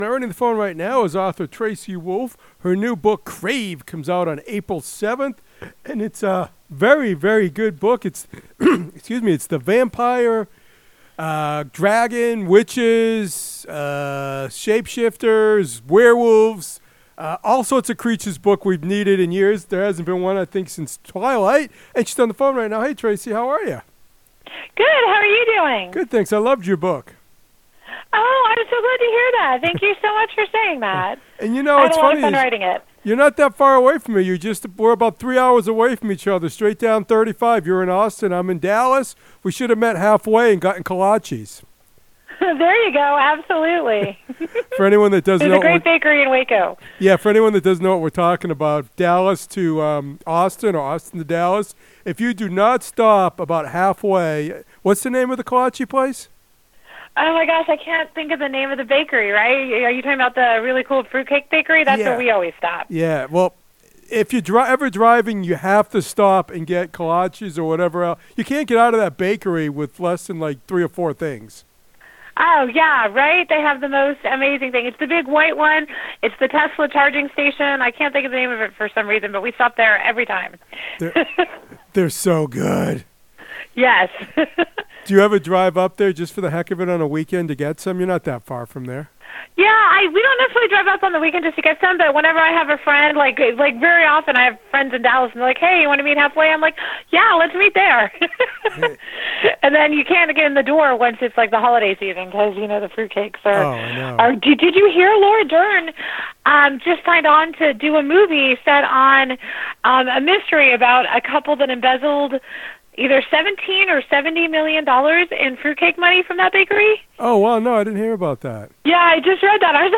Interview: Author ‘Tracy Wolff’ Talks Her New Vampire Novel Crave